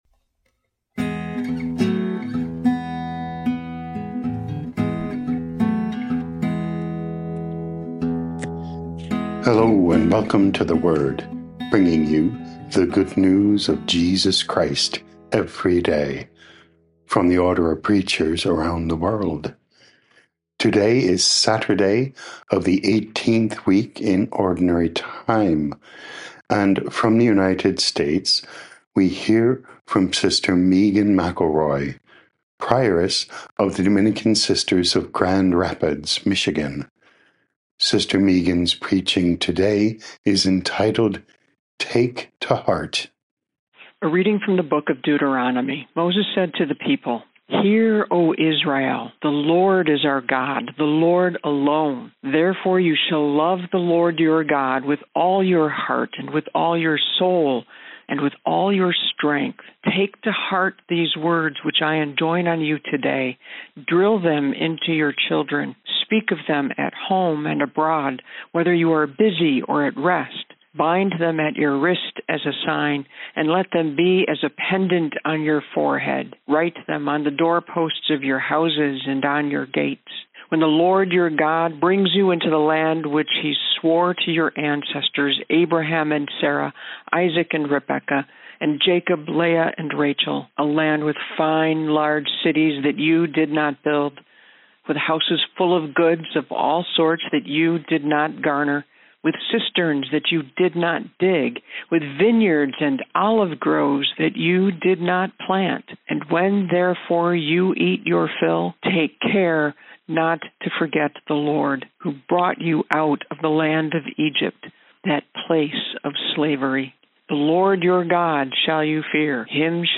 9 Aug 2025 Take to Heart Podcast: Play in new window | Download For 9 August 2025, Saturday of week 18 in Ordinary Time, based on Deuteronomy 6:4-13, sent in from Royal Oak, Michigan, USA.
Preaching